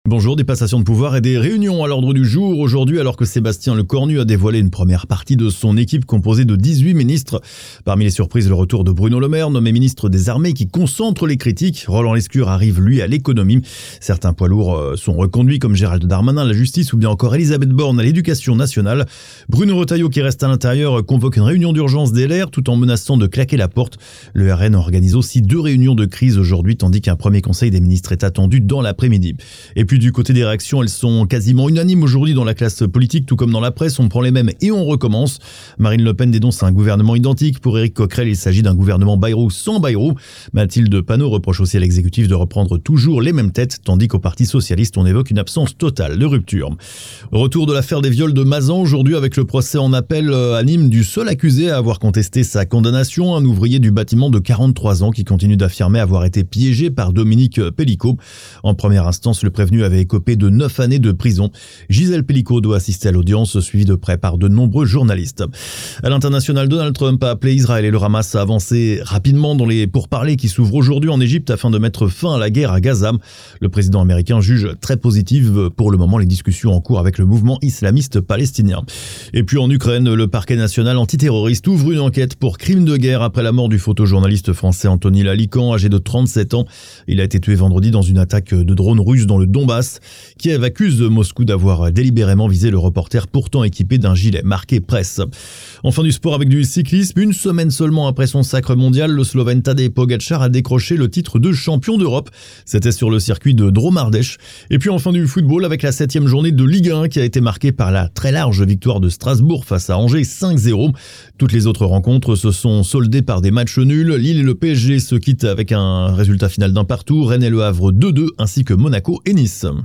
Flash infos 06/10/2024